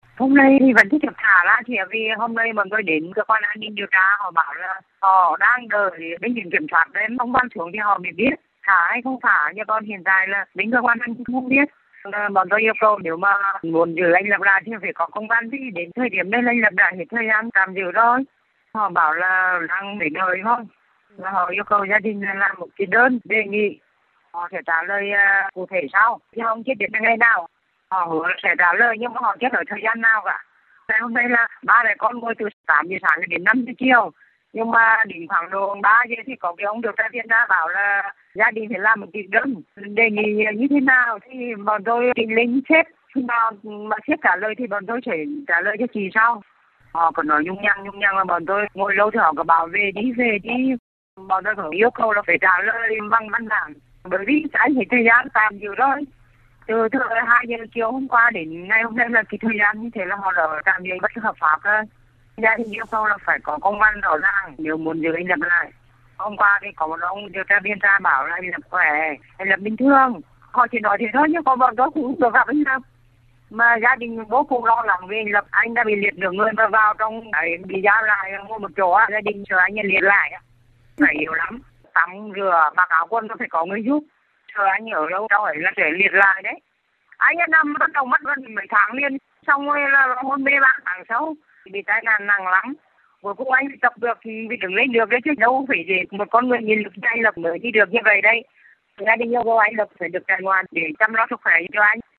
phỏng vấn